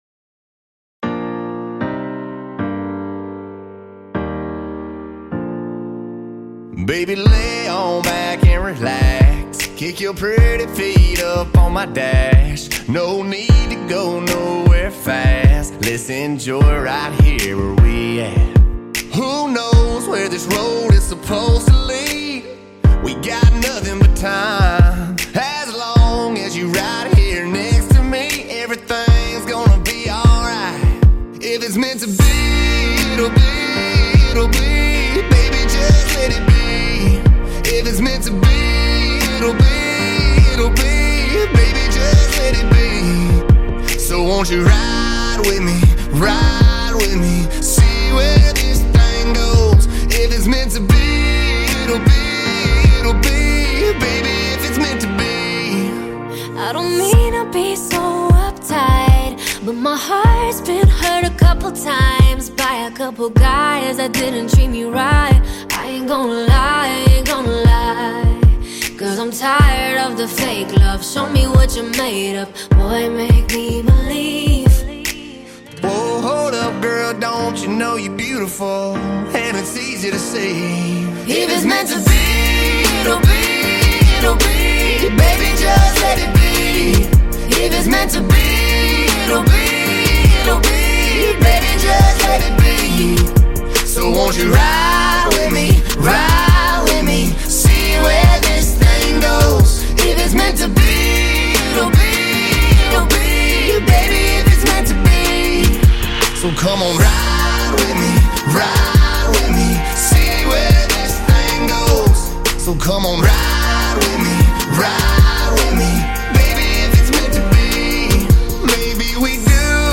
the amazing love song was released on October 24th